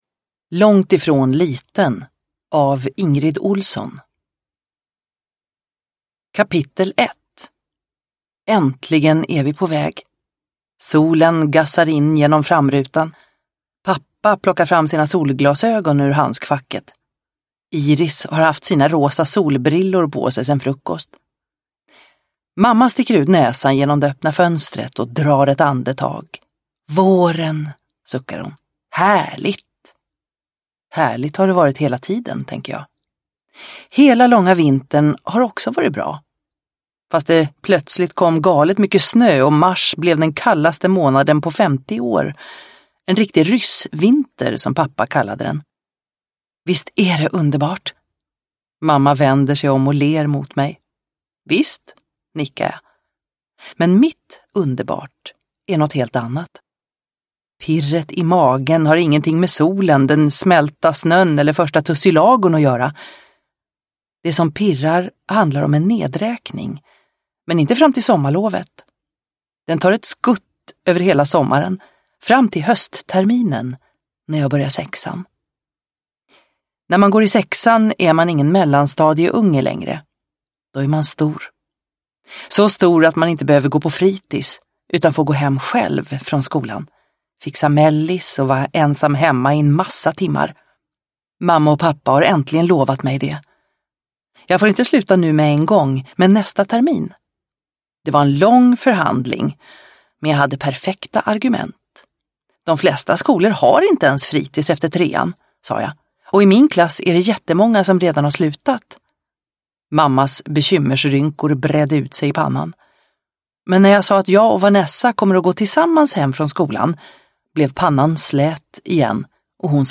Långt ifrån liten – Ljudbok – Laddas ner